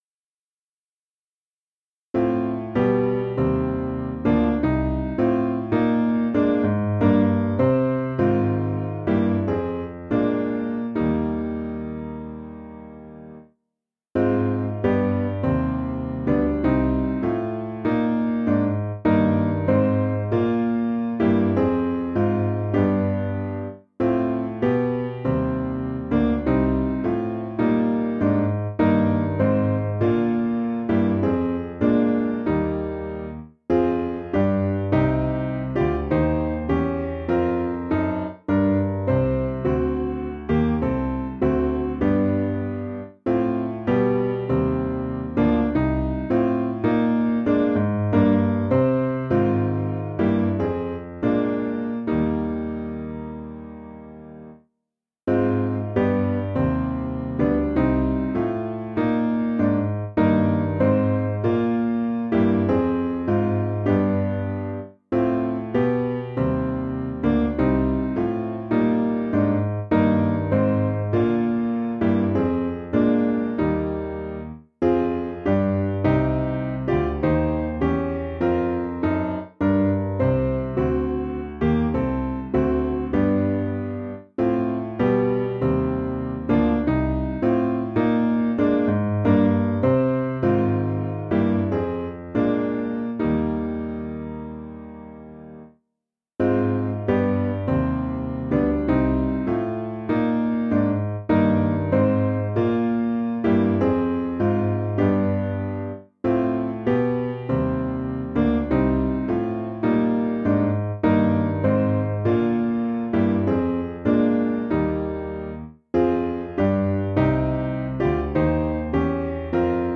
Information about the hymn tune SCHUMANN (Schumann 23671).
Meter: 8.7.8.7 D
Key: E♭ Major